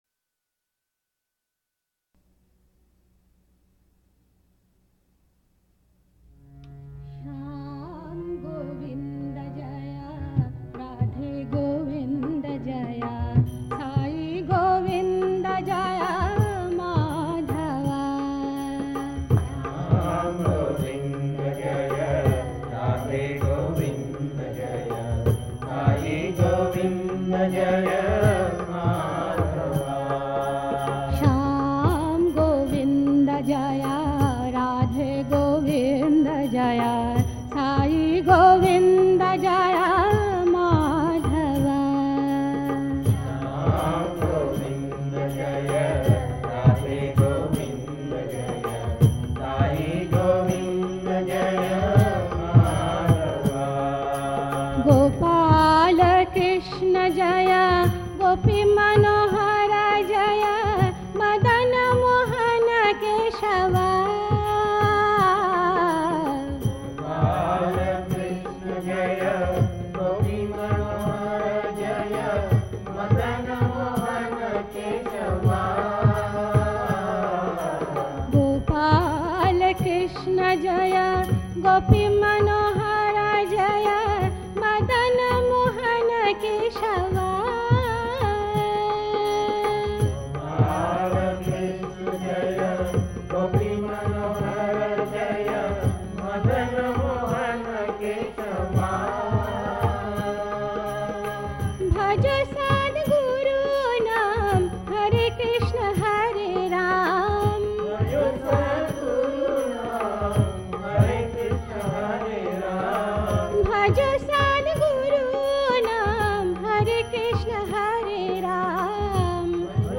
1. Devotional Songs
Gavati 8 Beat  Men - 4 Pancham  Women - 1 Pancham
Gavati
8 Beat / Keherwa / Adi
4 Pancham / F
1 Pancham / C
Lowest Note: d2 / A (lower octave)
Highest Note: S / C (higher octave)